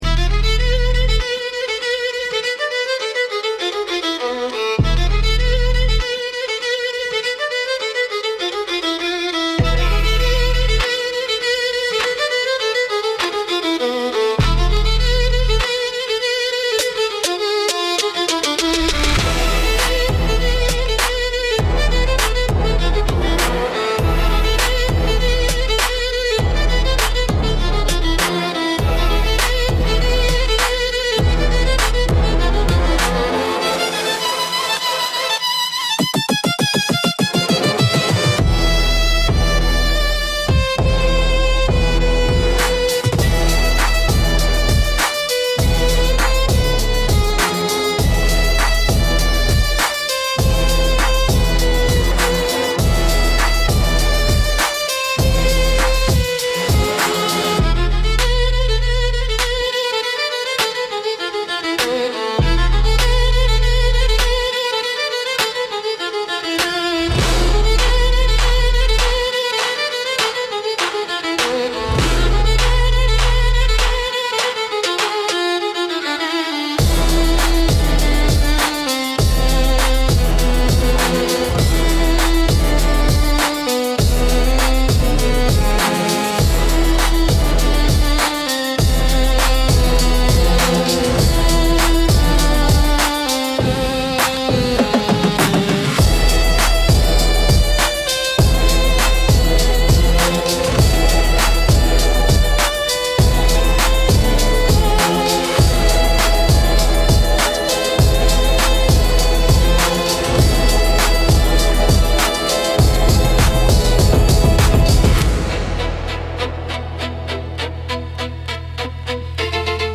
Instrumental - Heat Between the Lines